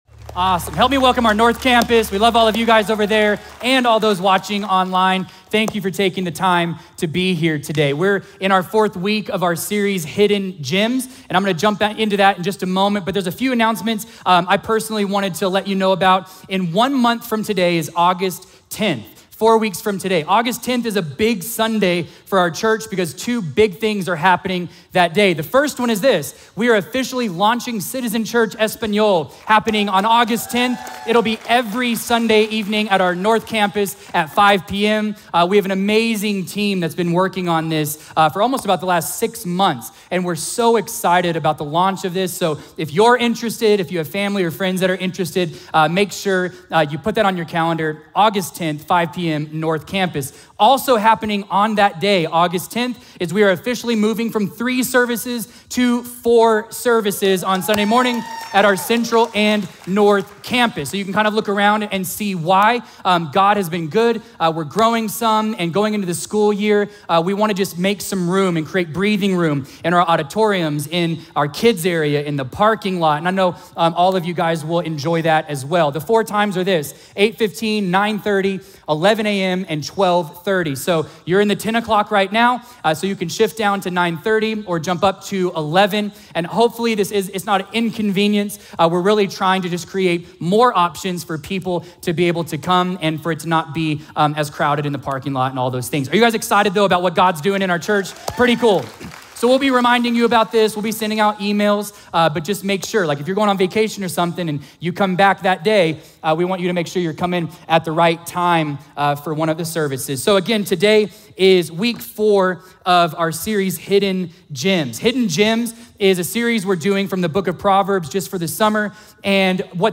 A message from the series "Homework." Join us in Week 4 of our 'Home Work' series as we explore the beauty and purpose of singleness!